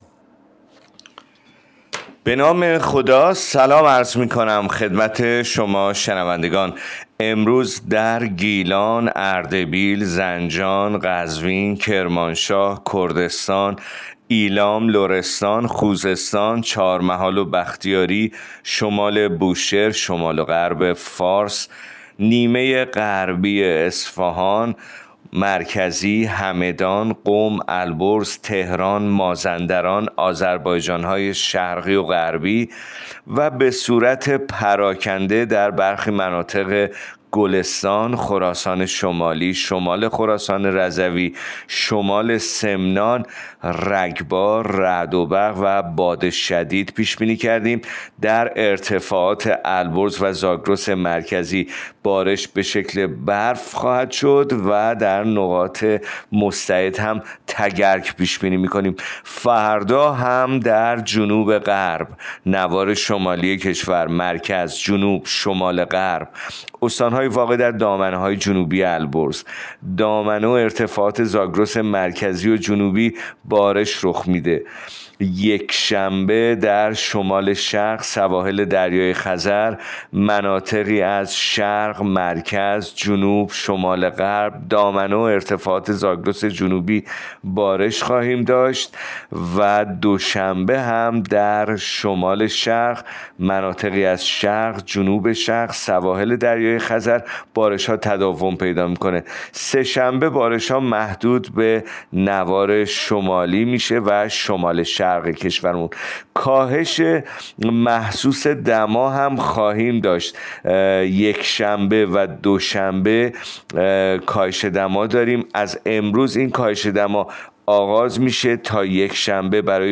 گزارش رادیو اینترنتی پایگاه‌ خبری از آخرین وضعیت آب‌وهوای ۱ فروردین؛